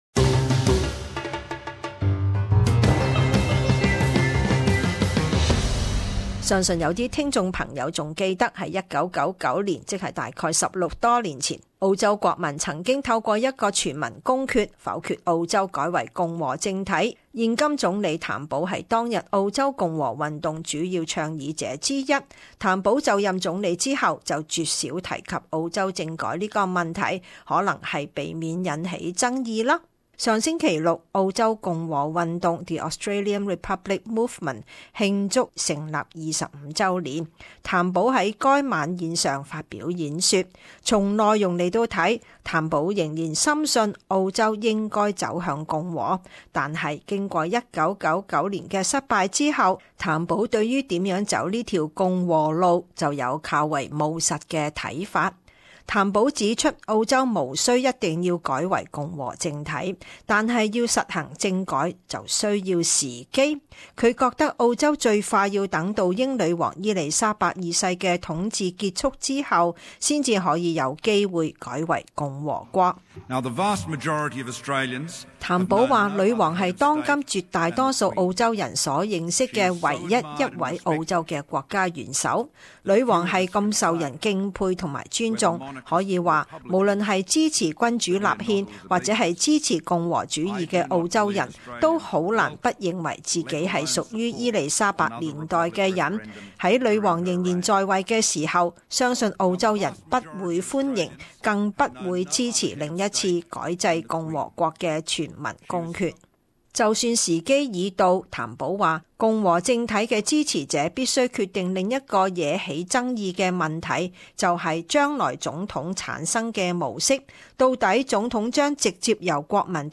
【時事報導】澳州離共和政體還有多遠？